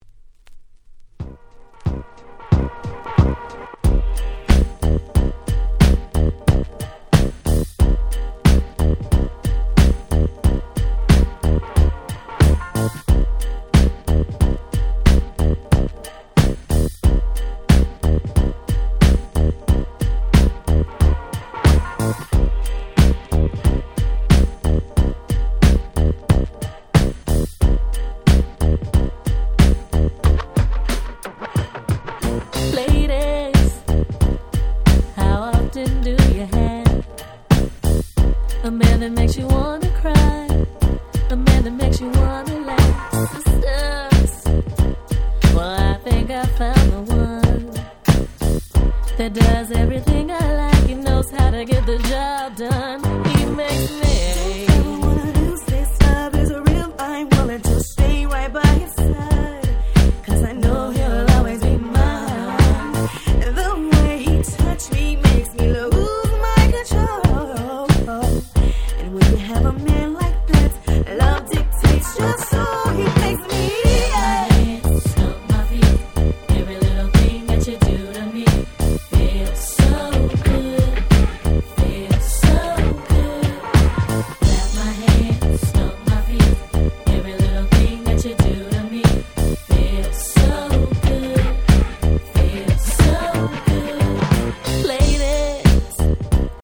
95' Smash Hit R&B !!
決して派手さは無いものの、彼女達のコーラスワークを十二分に堪能出来る素晴らしいHip Hop Soul